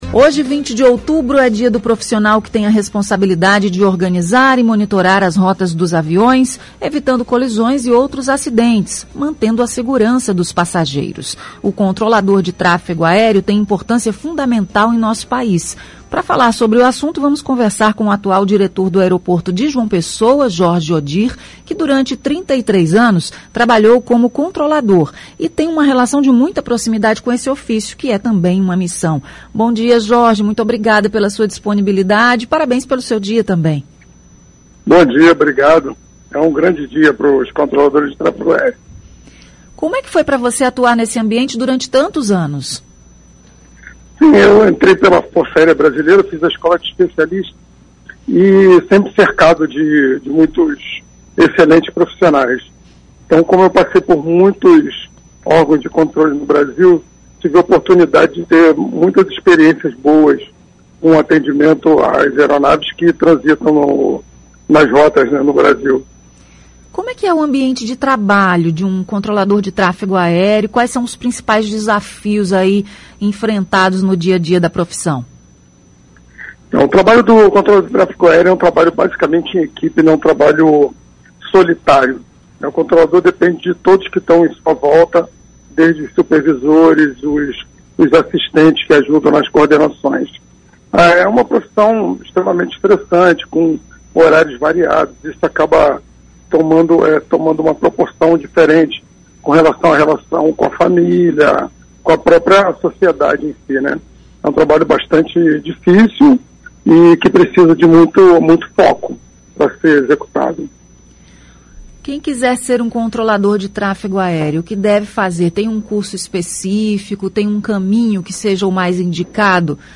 Entrevista: Você sabe a importância do controlador de voo para a segurança?